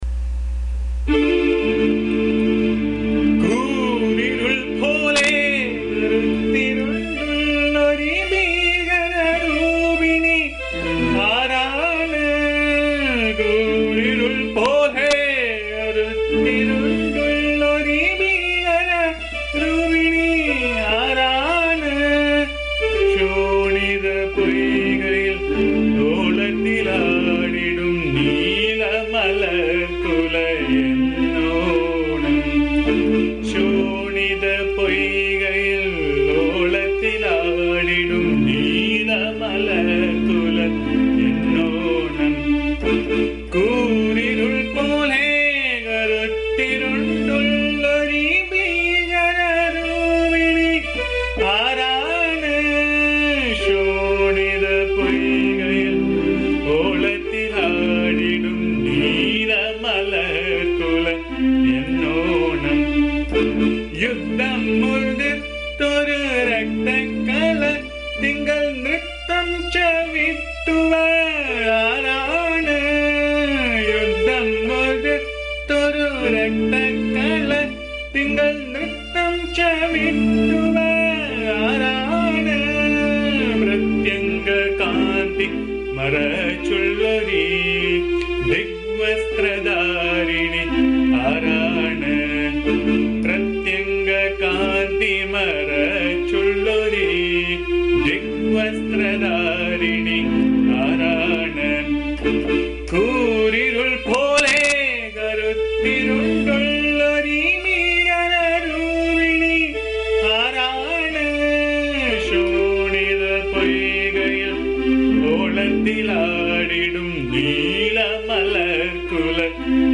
This is a song that speaks about the external form of AMMA and beautifully sung by Swami Amritasvaroopananda set in the Raga Bhimpalasi (Abheri). The song speaks about AMMA's external form as unattractive and fierce but internally AMMA is filled with compassion, love and attraction.
The song has been recorded in my voice which can be found here.
AMMA's bhajan song